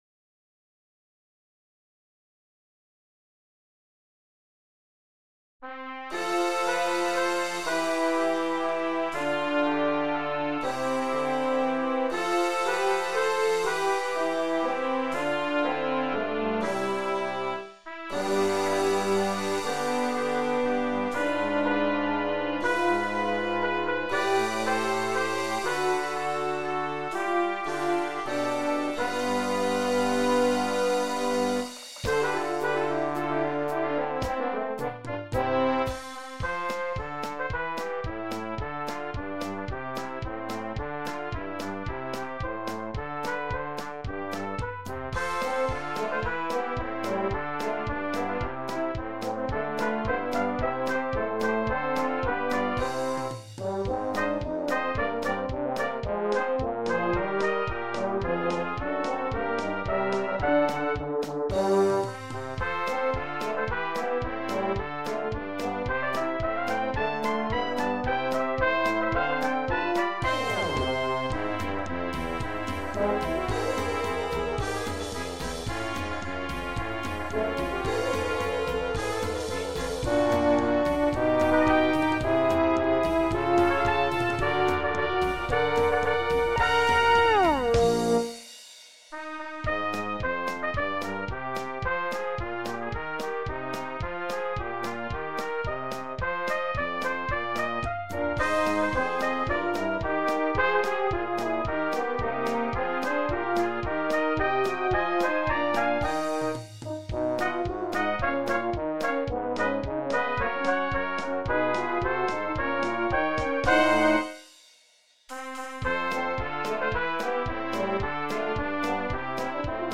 Arranged for 2 Trumpets, Horn in F, Trombone and Drums..
Finale generated audio with drums: